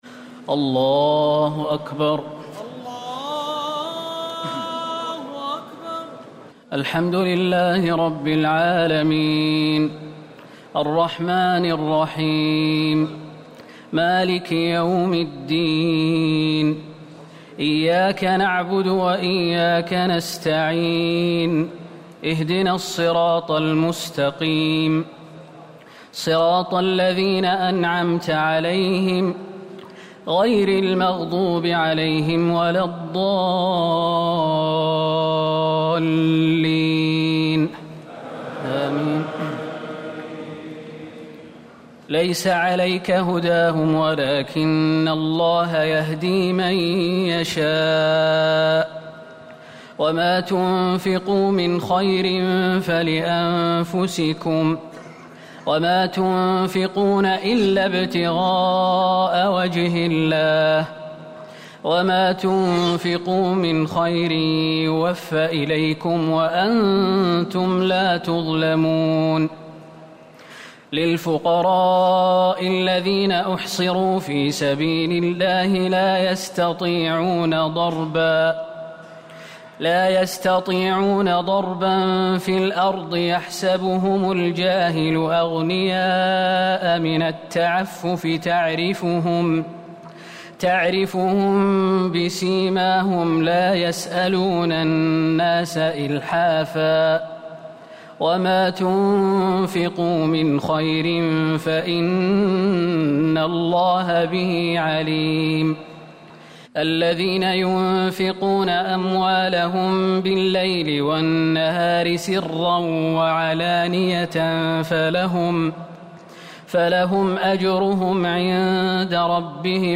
تراويح الليلة الثالثة رمضان 1439هـ من سورتي البقرة (272-286) و آل عمران (1-63) Taraweeh 3st night Ramadan 1439H from Surah Al-Baqara and Surah Aal-i-Imraan > تراويح الحرم النبوي عام 1439 🕌 > التراويح - تلاوات الحرمين